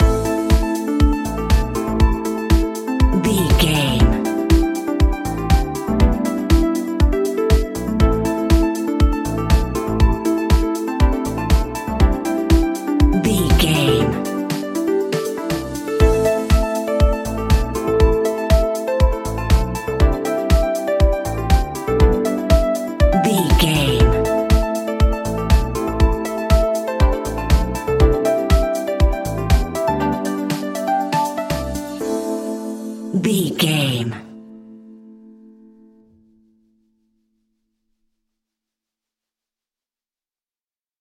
royalty free music
Aeolian/Minor
groovy
uplifting
energetic
electric piano
bass guitar
synthesiser
drums
funky house
nu disco
upbeat